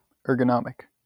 wymowa: